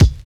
113 KICK 2.wav